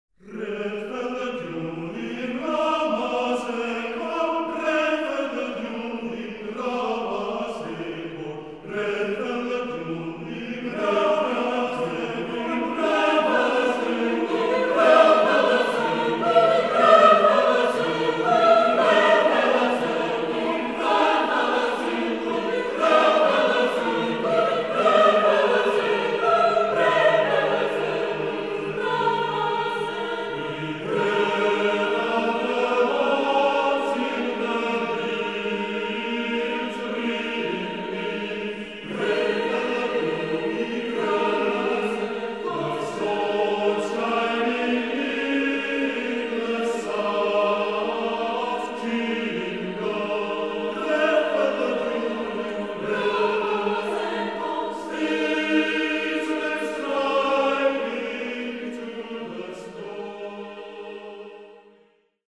as lowish quality MP3s